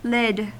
Ääntäminen
Ääntäminen : IPA : /lɪd/ US : IPA : [lɪd]